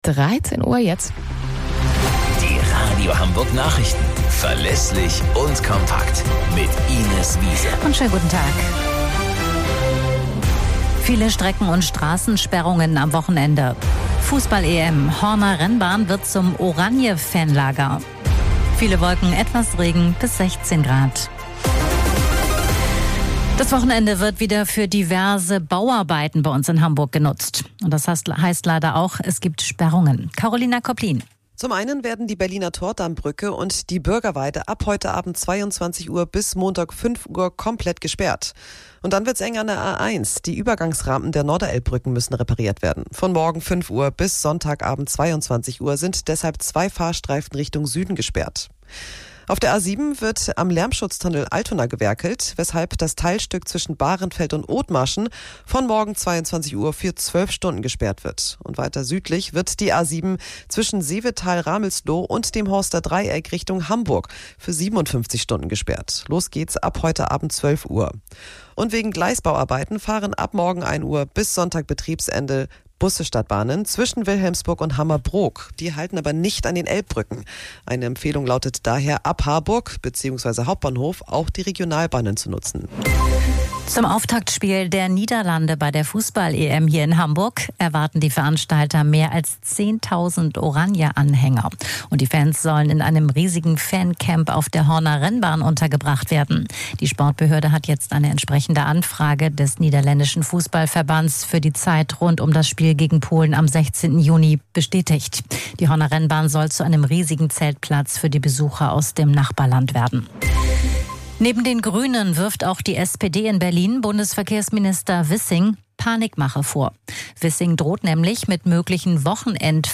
Radio Hamburg Nachrichten vom 12.04.2024 um 13 Uhr - 12.04.2024